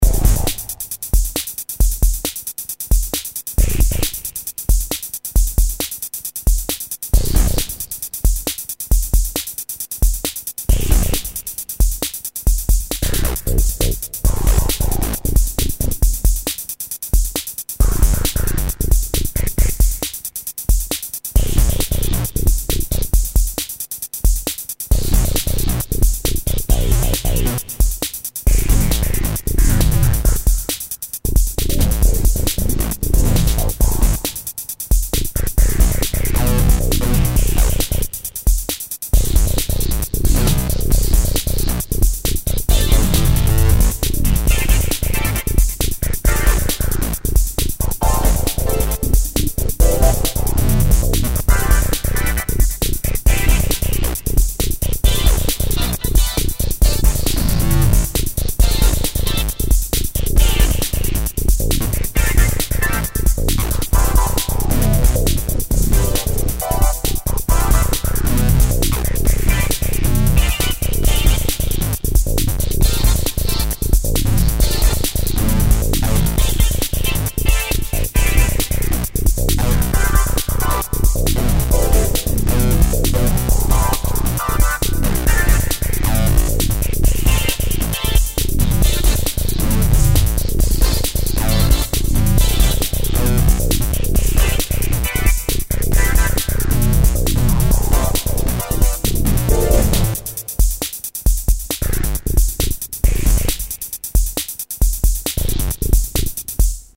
Korg TRITON Extreme / samples / combinations
заводская комбинация B004 - "Security Code"